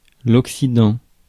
Ääntäminen
Synonyymit ouest couchant ponant Ääntäminen France (Paris): IPA: /ɔk.si.dɑ̃/ Haettu sana löytyi näillä lähdekielillä: ranska Käännös 1. lääs Suku: m .